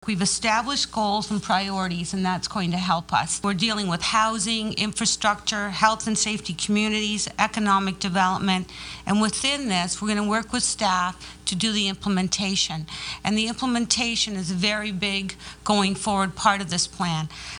Councillor Karen Sharpe says the new plan will touch on a lot of issues.